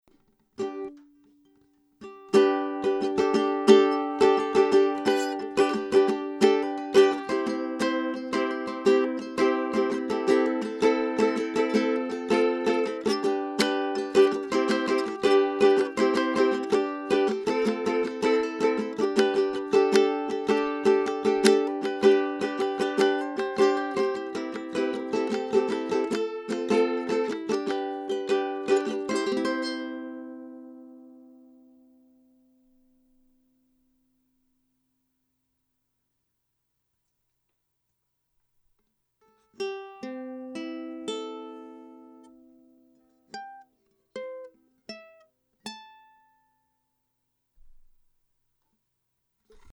Concert Ukulele #6 – SOLD
Concert Ukulele #6 “paint” Spruce top, rosewood sides/back, mahogany neck.
Shellac finish.